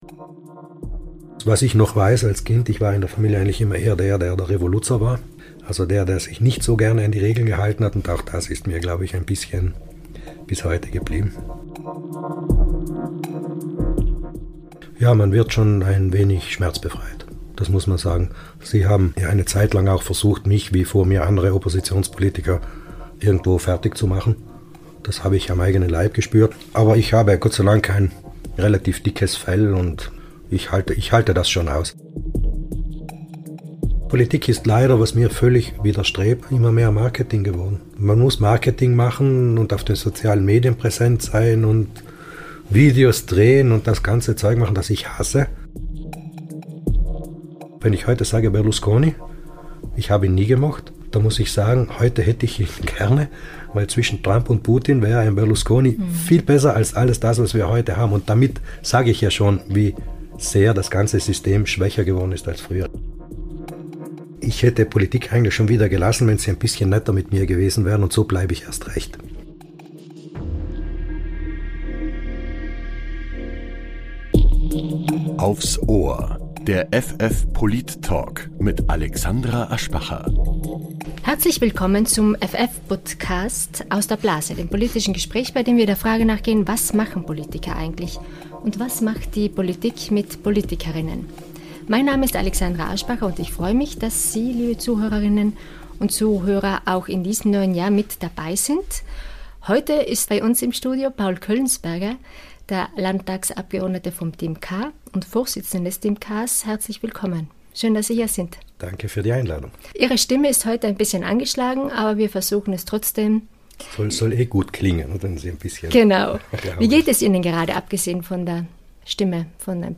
Der Politik-Talk